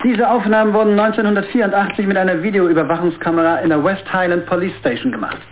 Amiga 8-bit Sampled Voice
polizeisprecher.mp3